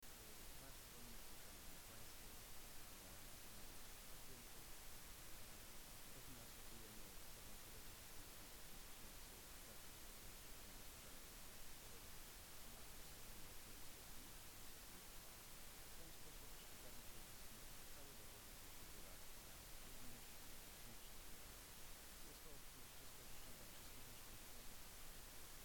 Wypowiedź p. Leszka Ruszczyka, wicemarszałka zarządu województwa mazowieckiego,